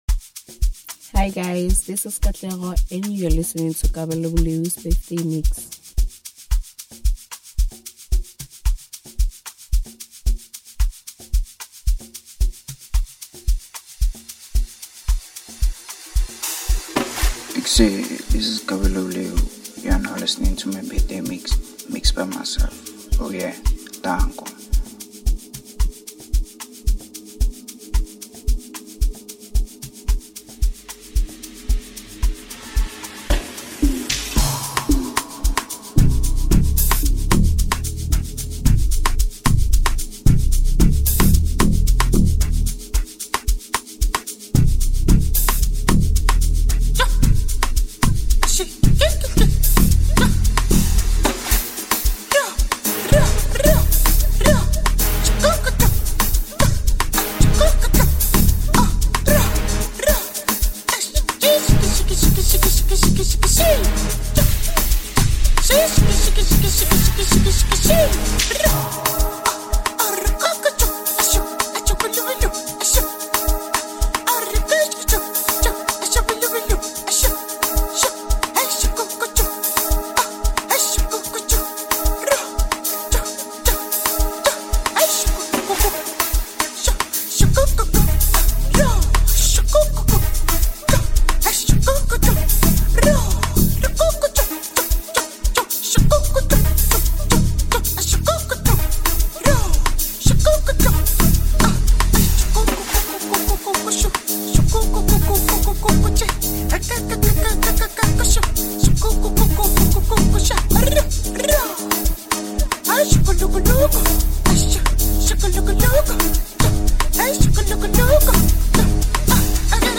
brimming with true piano bangers